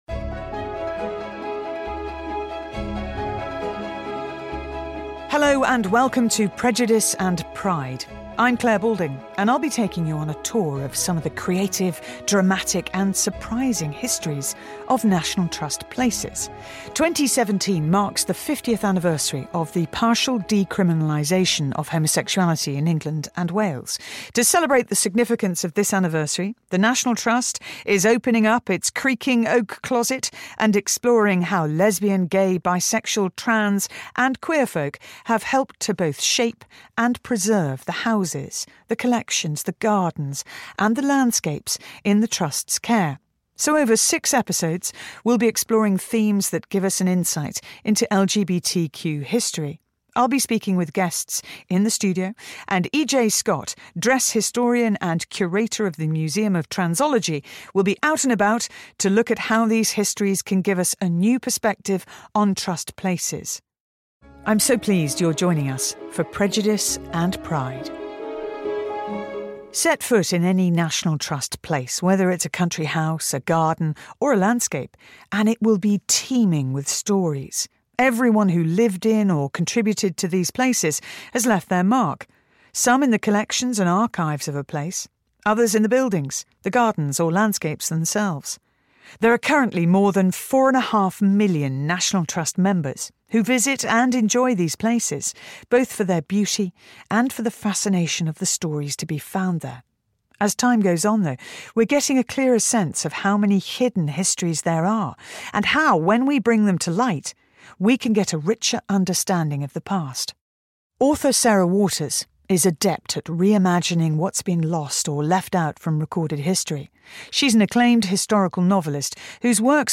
Clare Balding presents a series exploring the dramatic and surprising LGBTQ history of National Trust places.
Reading by Sarah Waters.